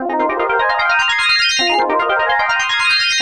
FILTERORGANX 1.wav